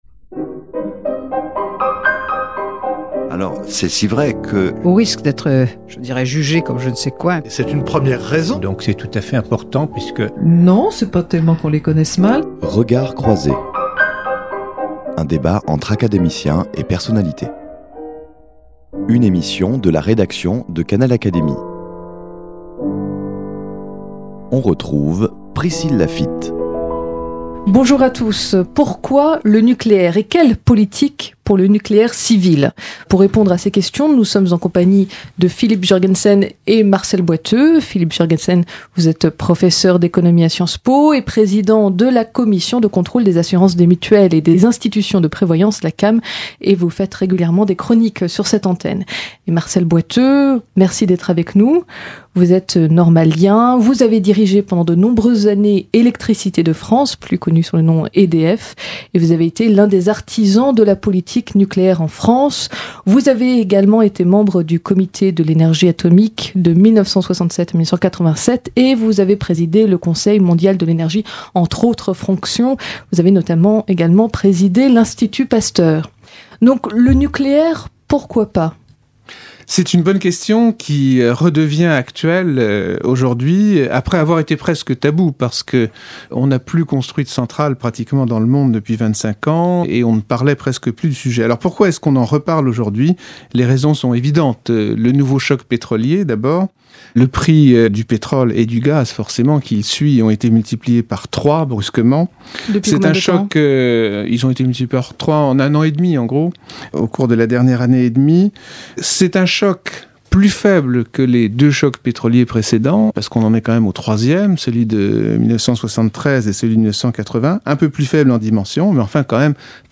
voici une émission en compagnie de deux fervents défenseurs de la politique nucléaire